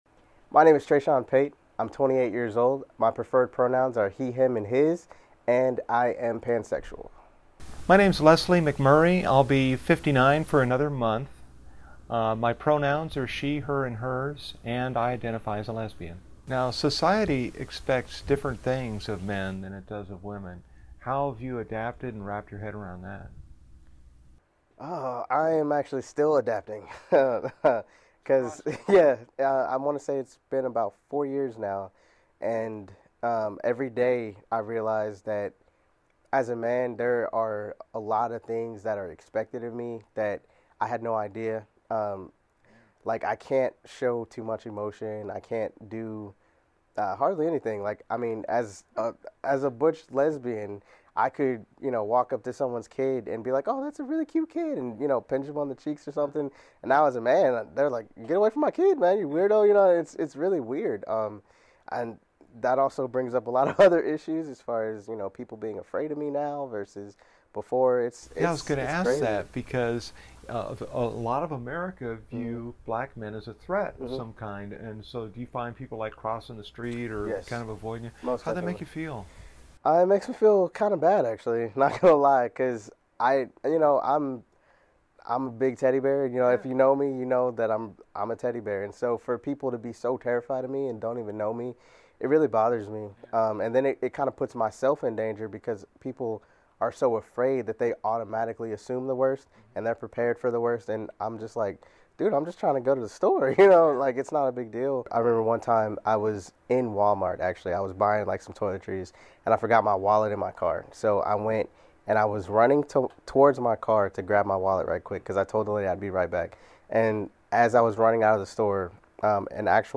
The Dallas Morning News held conversations to mark both Pride Month and the 15th anniversary of the Lawrence v. Texas ruling, a U.S. Supreme Court decision that overturned Texas’ ban on so-called homosexual conduct.